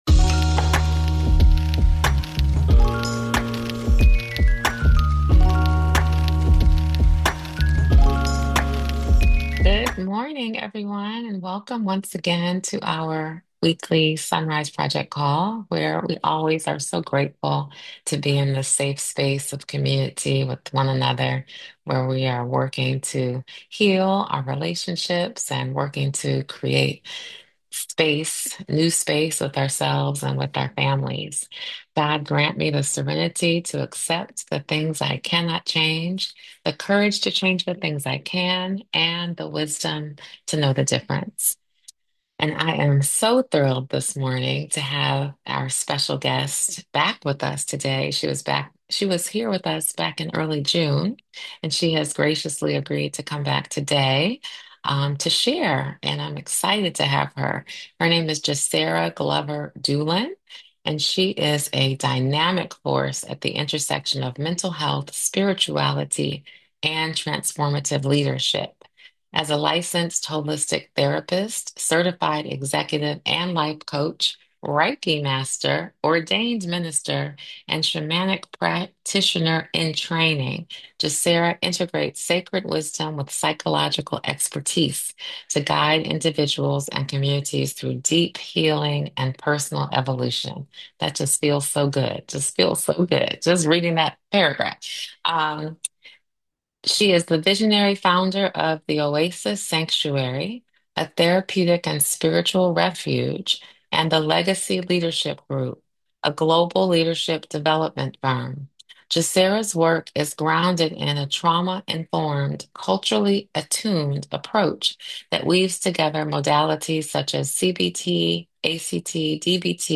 This conversation offers encouragement and practical insight for anyone seeking authenticity in life, relationships, and faith.